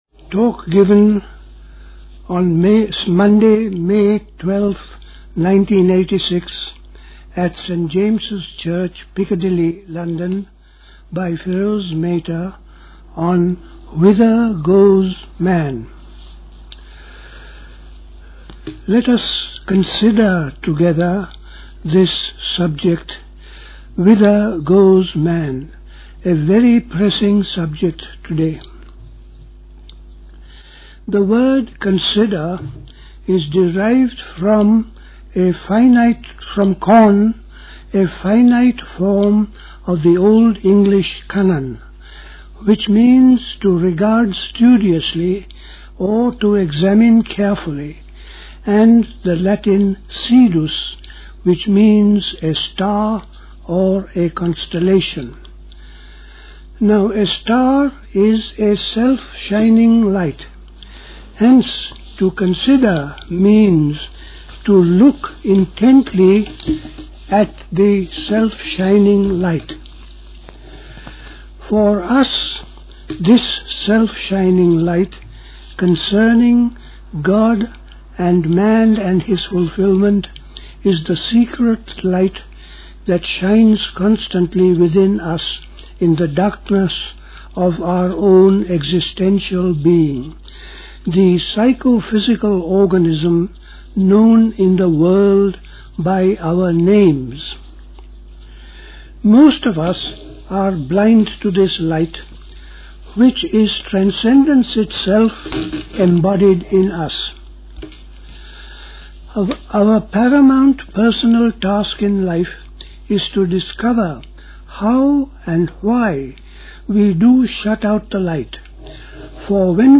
A talk
at St. James’s Church, Piccadilly, London on 12th May 1986